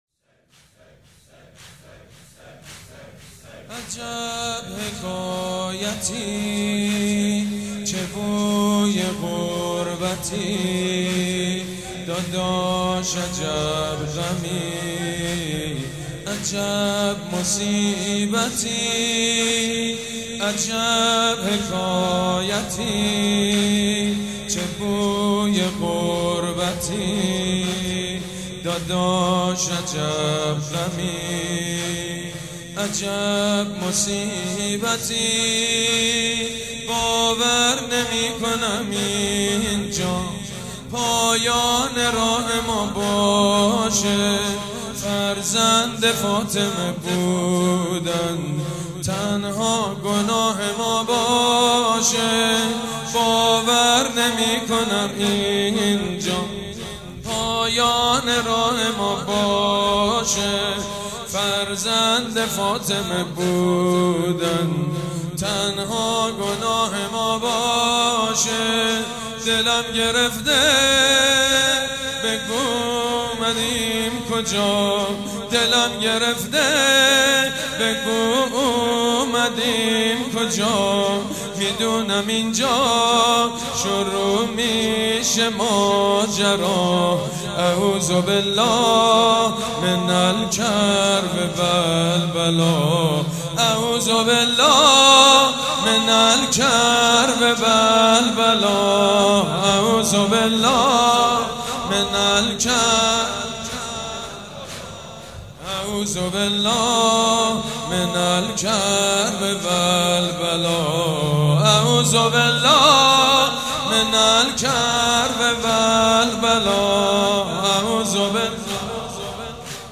واحد | عجب حکایتی، چه بوی غربتی
مداحی حاج سید مجید بنی فاطمه | شب دوم محرم 1396 | هیأت ریحانه الحسین(س)